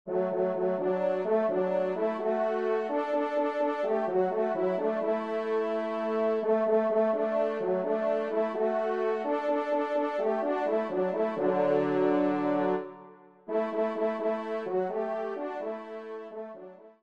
Trompe 3